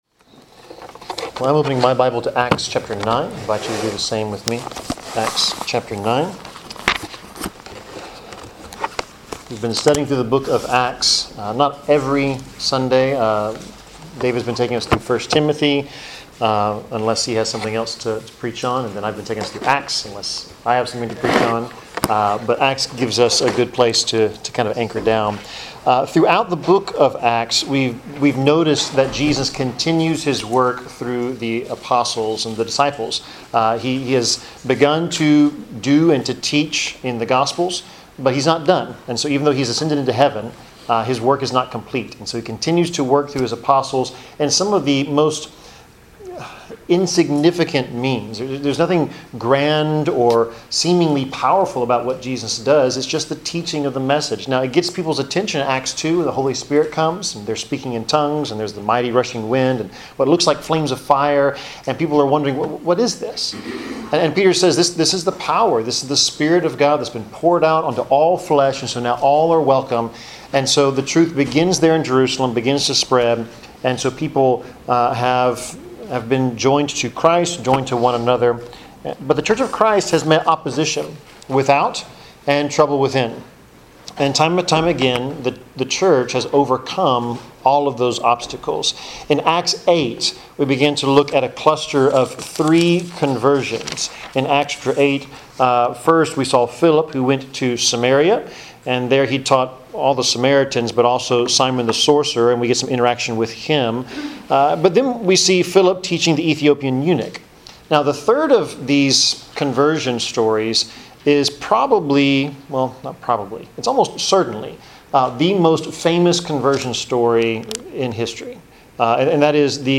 Passage: Acts 9 Service Type: Sermon